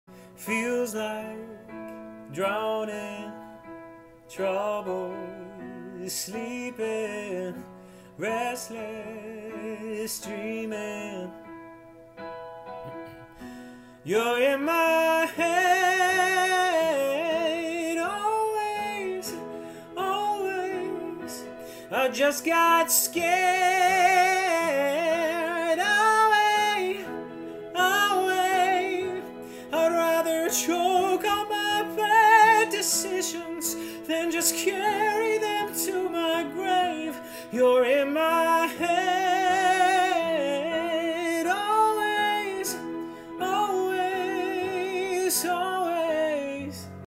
Pop-Mix • MT Belt • Classical Mix